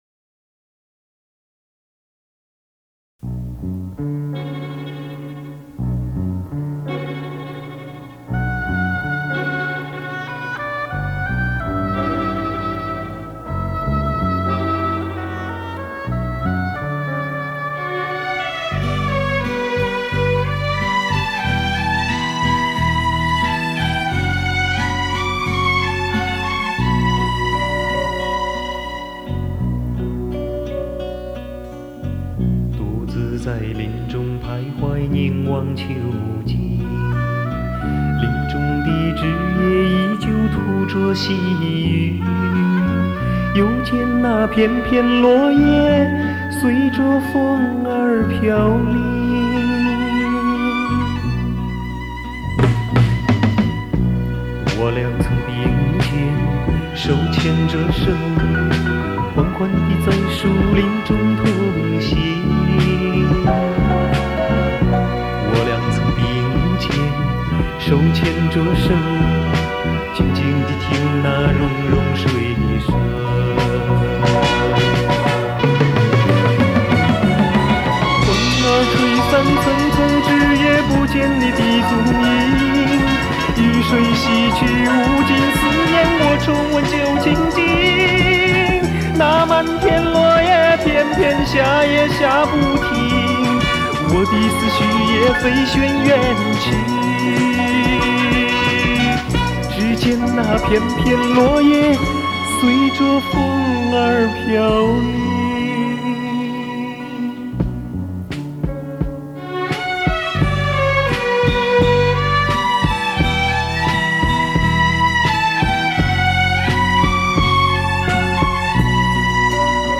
台湾校园歌曲